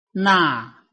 臺灣客語拼音學習網-客語聽讀拼-海陸腔-單韻母
拼音查詢：【海陸腔】na ~請點選不同聲調拼音聽聽看!(例字漢字部分屬參考性質)